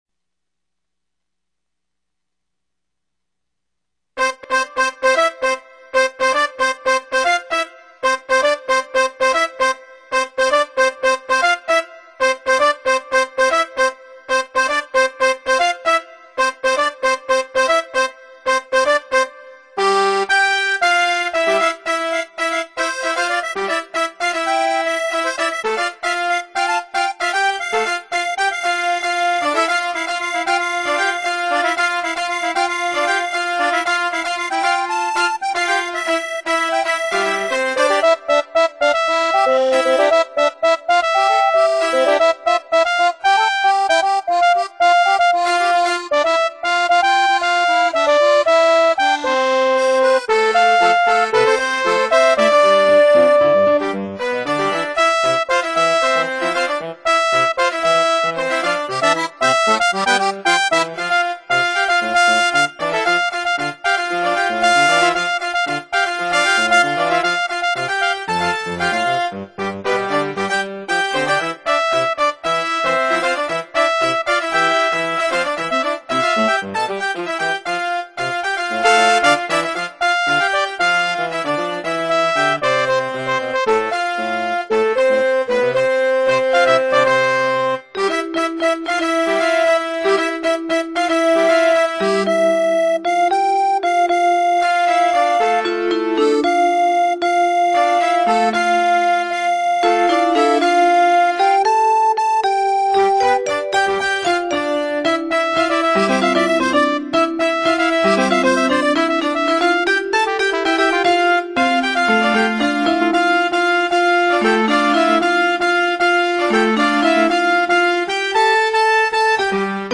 ハイキング日和当初は渋いイメージを狙っていたが、出来上がってみると全然渋くない。